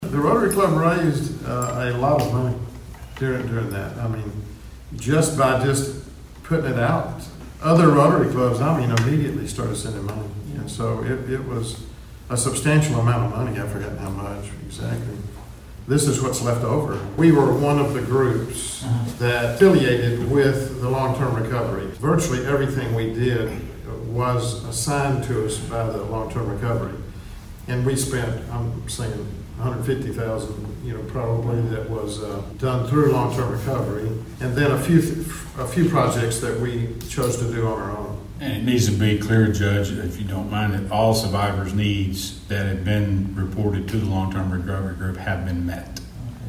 General Judge Tommy Moore explains where the funds came from